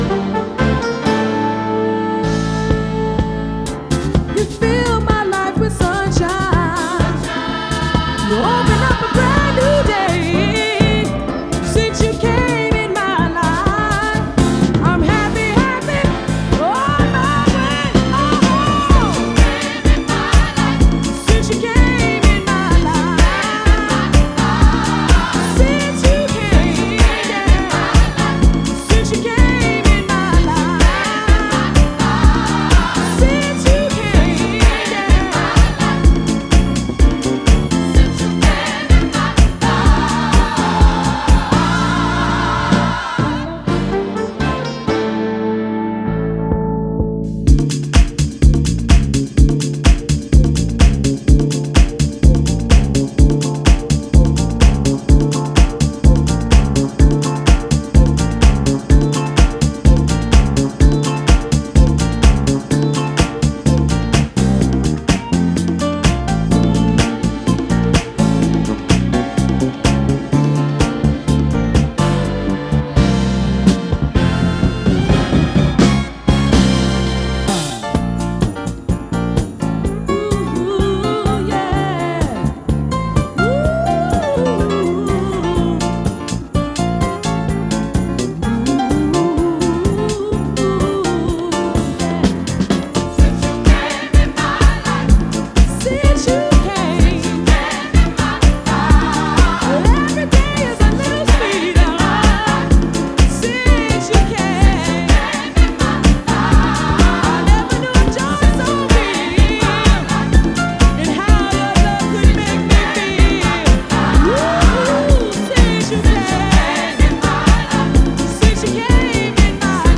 Styl: Disco, House